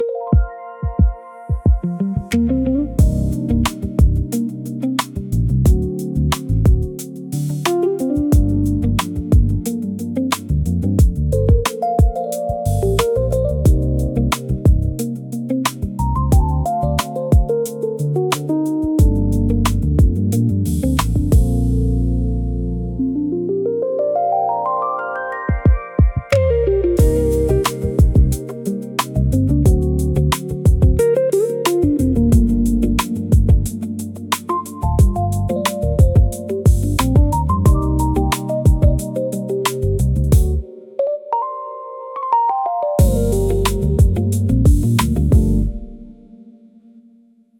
上質感とやわらかさのバランス。
R&B Clean 高級感